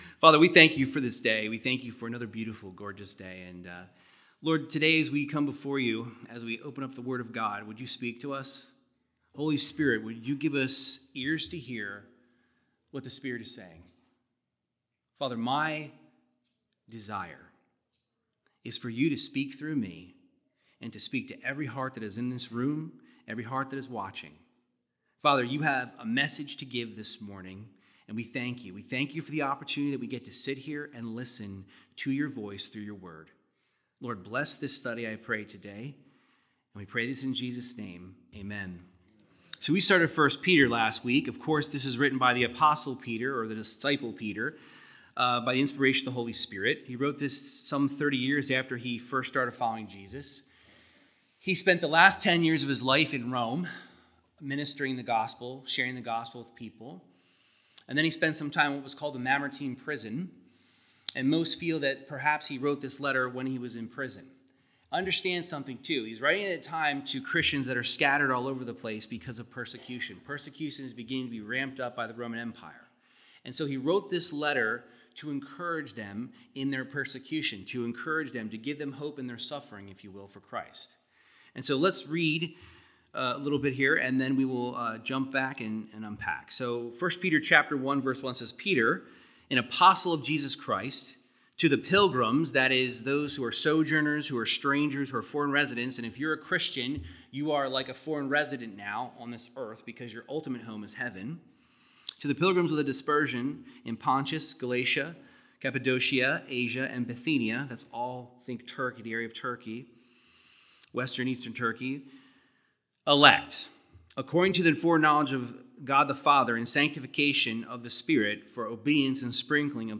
Verse by verse Bible teaching in 1 Peter 1:3-10 discussing how no matter the hard times we go through, we have a secure salvation and incorruptible inheritance.